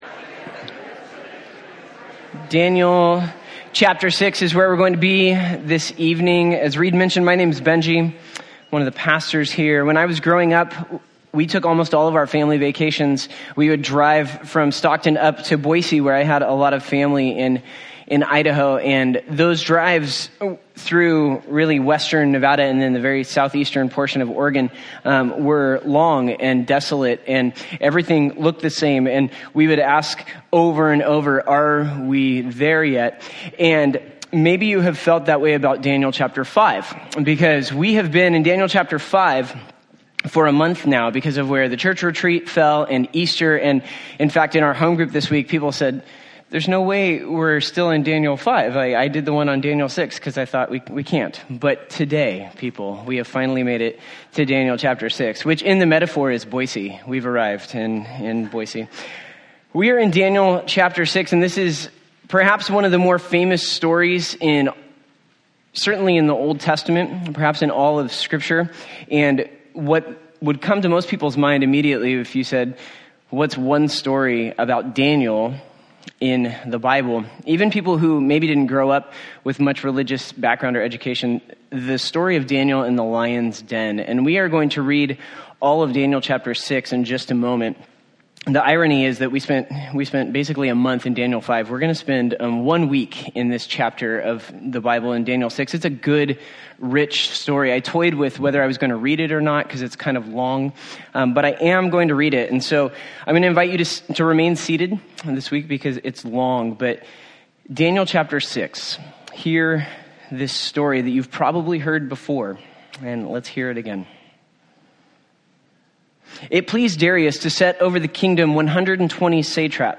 Daniel Passage: Daniel 6:1-28 Service Type: Sunday